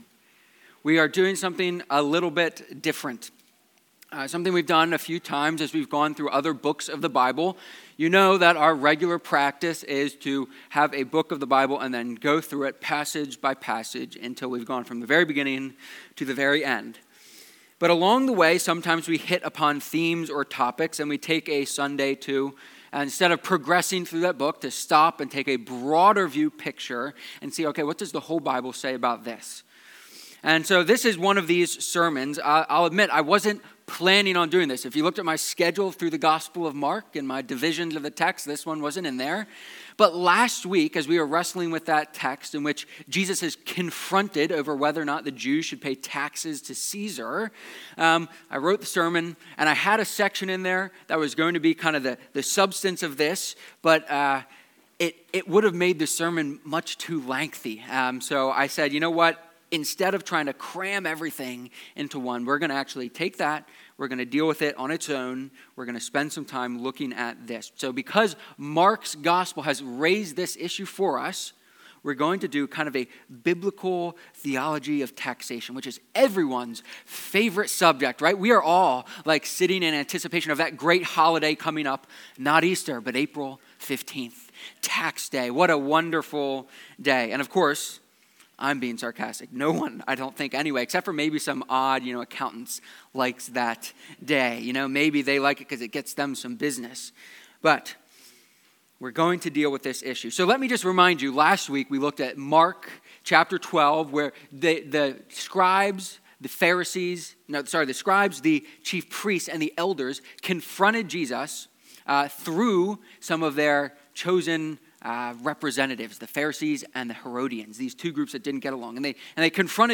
3-17-sermon.mp3